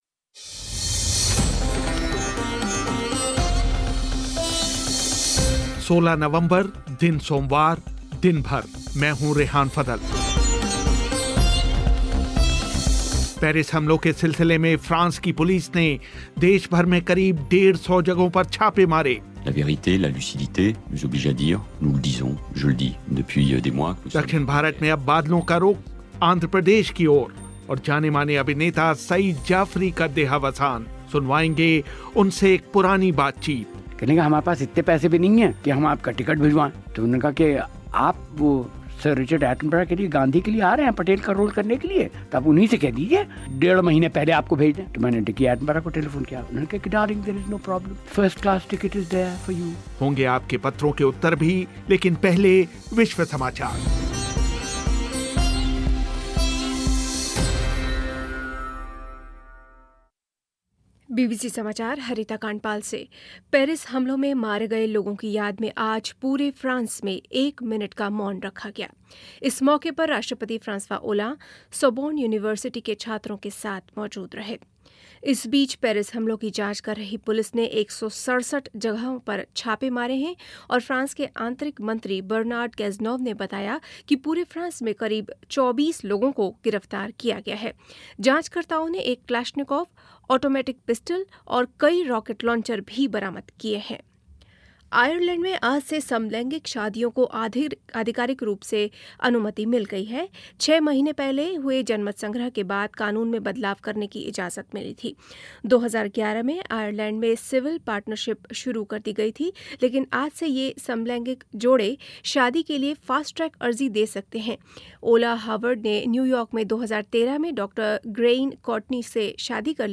पेरिस हमलावरों की तलाश में फ्रांस की पुलिस ने देशभर में तकरीबन डेढ़ सौ संदिग्ध जगहों पर छापे मारे जानेमाने अभिनेता सईद जाफ़री का निधन. सुनवाएंगे उनसे एक पुरानी बातचीत. दक्षिण भारत में बादलों का रुख़ अब आँध्रप्रदेश की ओर होंगे आपके पत्रों के उत्तर और खेल समाचार भी